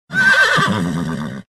Звуки ржания лошадей
На этой странице собрана коллекция натуральных звуков ржания лошадей.
Ржание для сборки